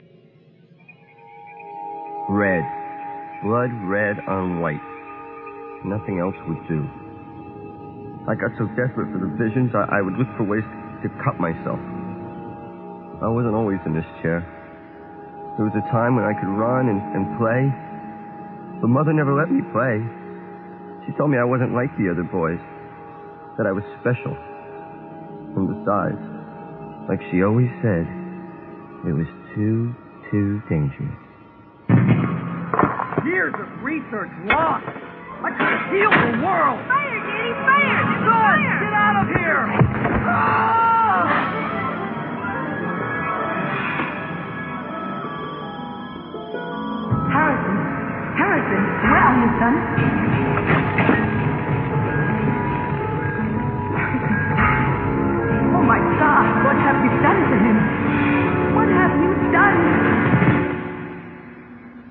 3. Para Filter: Hiss NR Alt7
You have basically recreated the "echo" right?